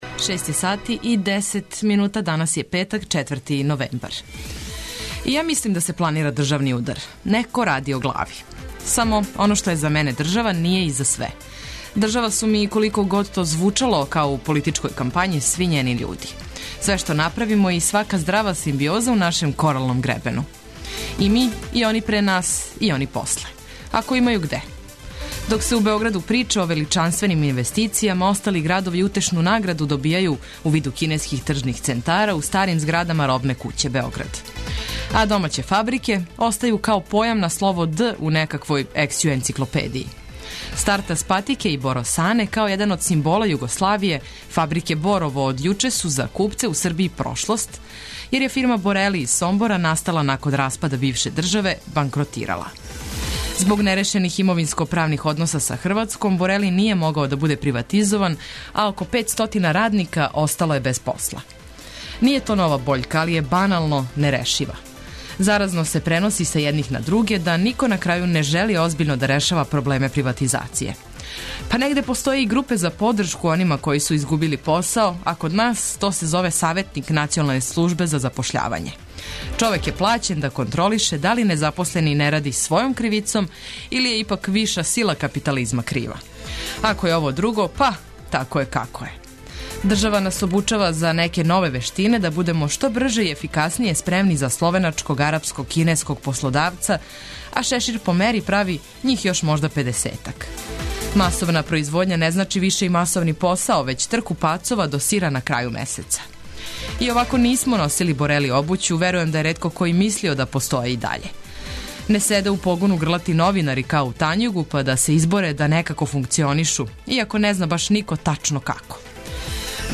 Уз битне информације, разноврсне теме, песму по песму - разбудићете се за нови дан!